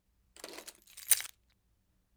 picking-up-keys.wav